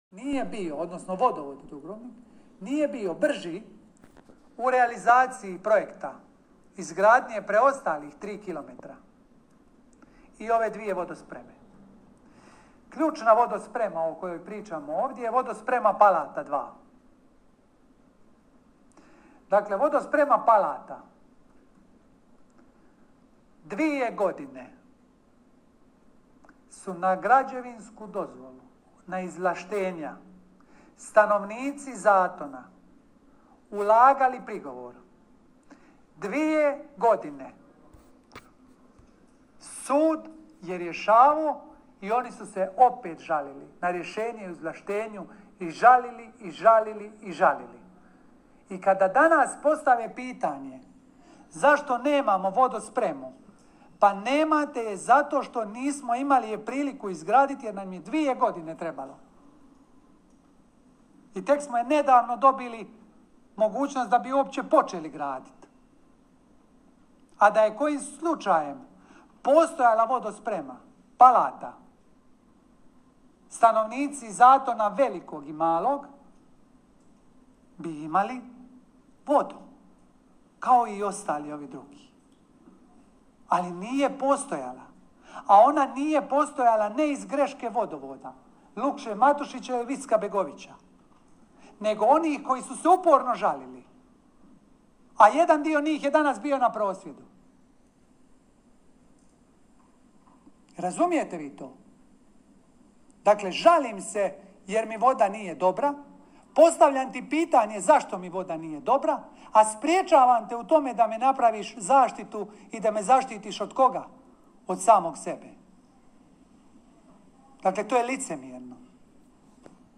U nastavku poslušajte izjavu gradonačelnika koji je postupak Zatonjanja nazvao licemjernim!